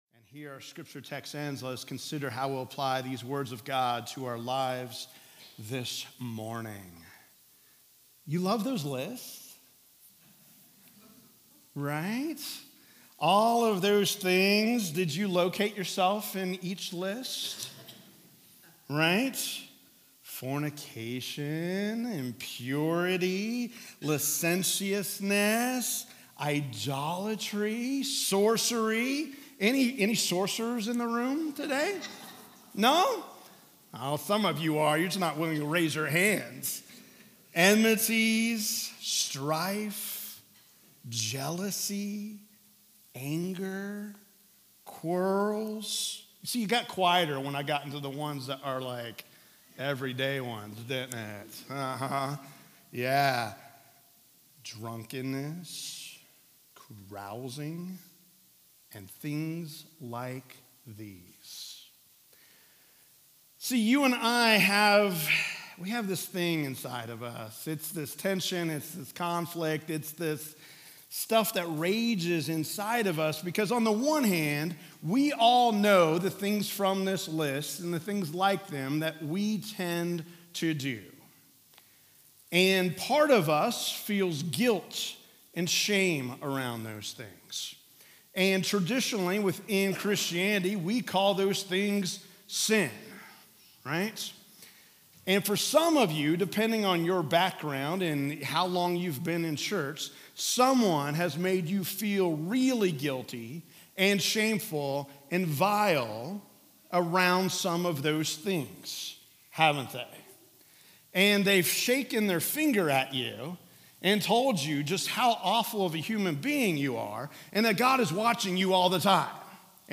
Sermons | Grace Presbyterian Church